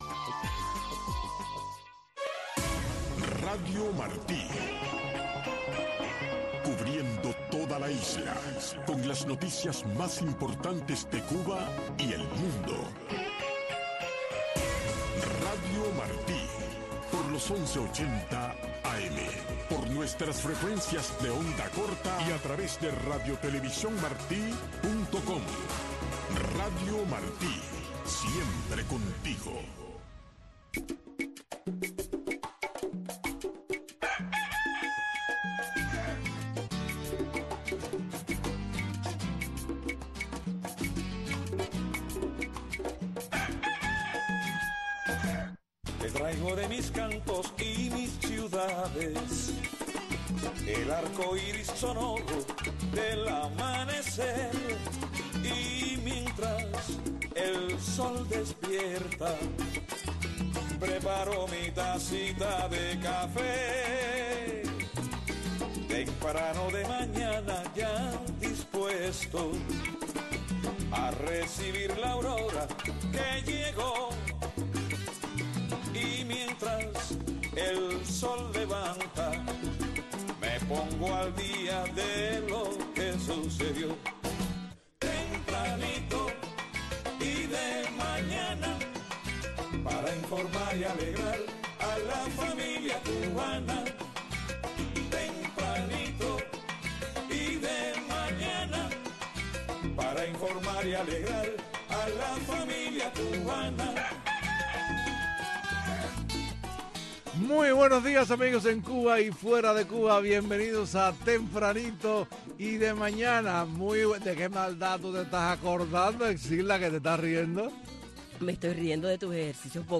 Disfrute el primer café de la mañana escuchando a Tempranito, una atinada combinación de noticiero y magazine, con los últimos acontecimientos que se producen en Cuba y el resto del mundo.